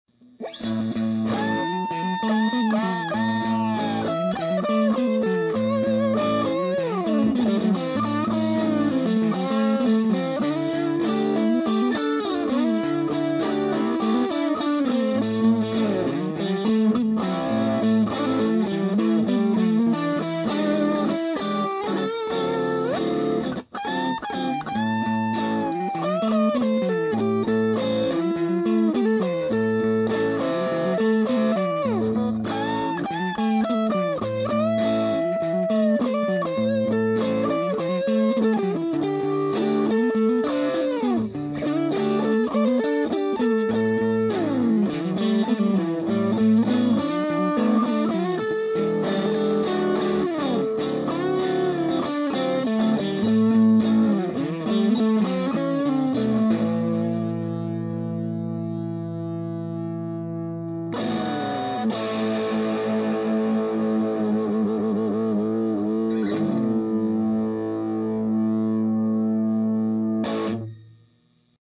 They were recorded using an SM57 into a computer using Sonar. No effects or eq added.
Next clip is all medium settings with a strat.
The left channel is one guitar using the sIII mode. The right is another using the boosted mode.
sIII_1_Strat2.mp3